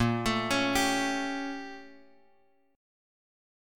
Listen to BbmM13 strummed